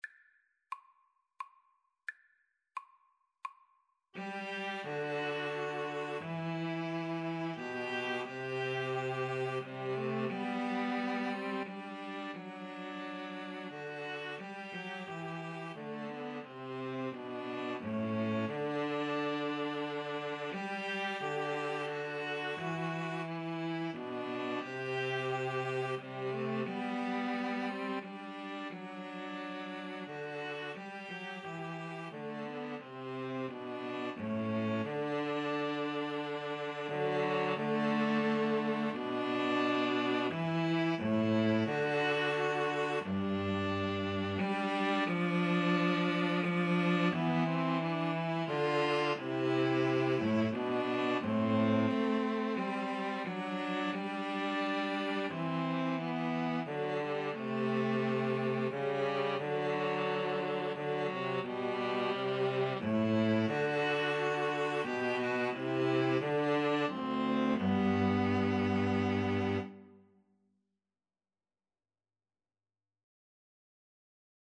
G major (Sounding Pitch) (View more G major Music for String trio )
Andante Cantabile ( = c.88)
3/4 (View more 3/4 Music)